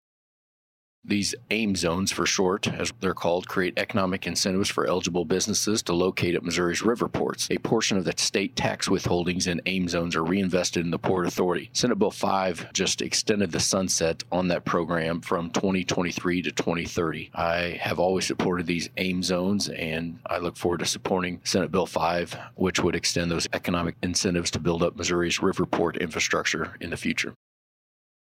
2. Senator Hoskins adds Senate Bill 5 is a measure that would modify provisions relating to certain infrastructure improvement districts.